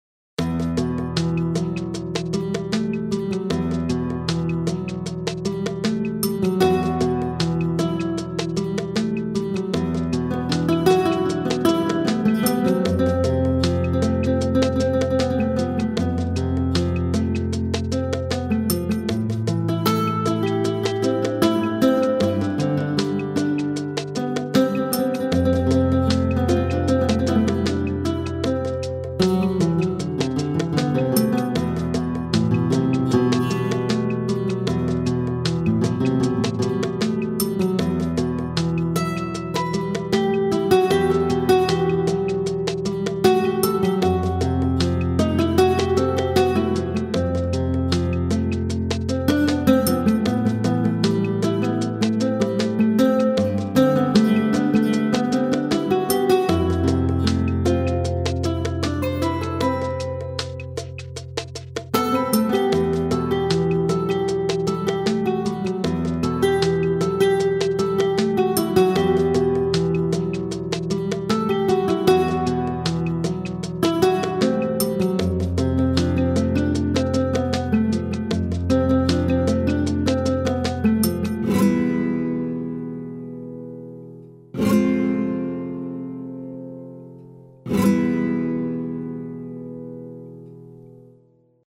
Музыкальный подклад к документально-историческим программам.